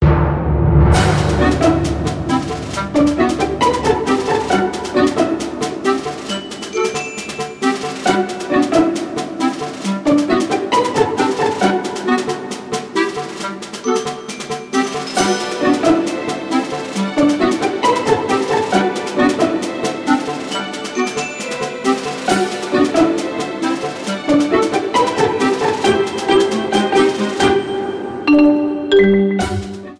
バトルBGMとして